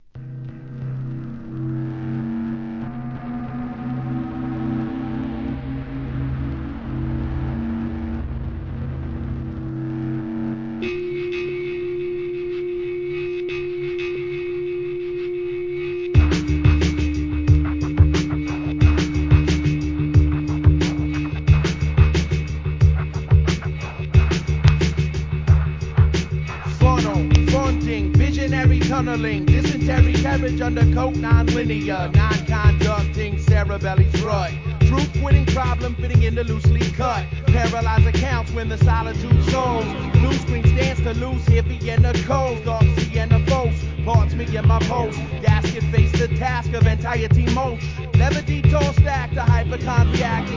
HIP HOP/R&B
アングラ・アブストラクトHIP HOP!!